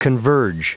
Prononciation du mot converge en anglais (fichier audio)
Prononciation du mot : converge